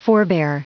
Prononciation du mot forebear en anglais (fichier audio)